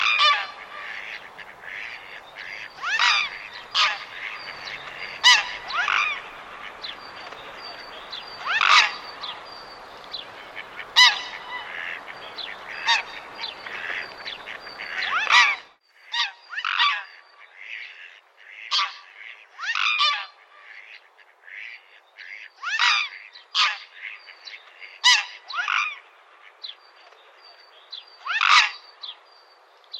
Cygne tuberculé - Mes zoazos
cygne-tubercule.mp3